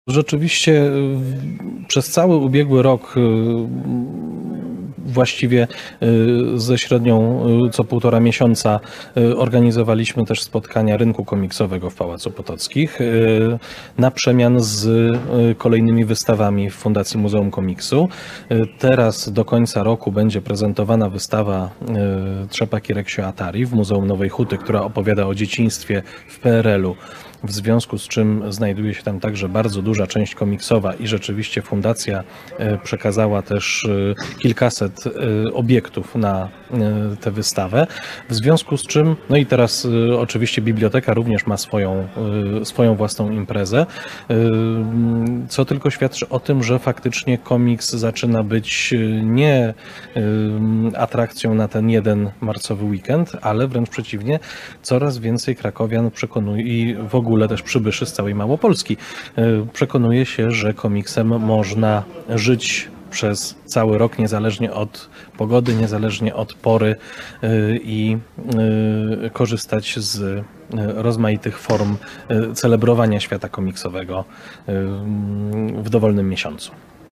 O tym, jak wypadła pierwsza z nich (czyli 12. Krakowski Festiwal Komiksu z 24-26.03) porozmawiałem z dwójką osób zaangażowanych w jej organizację, zaczynając od prostego pytania: Czy był szał?